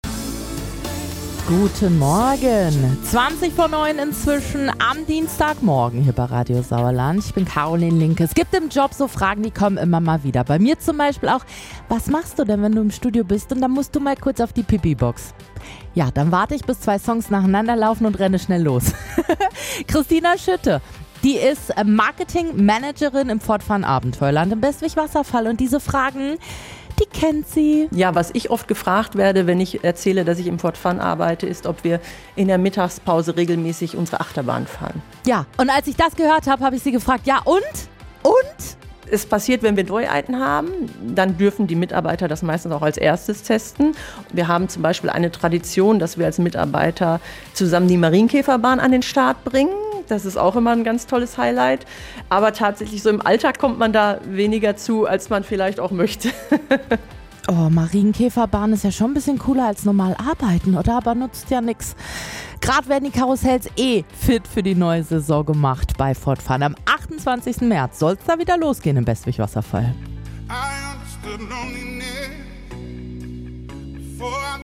Wir waren vor Ort und haben uns den Freizeitpark im Winterschlaf angeschaut.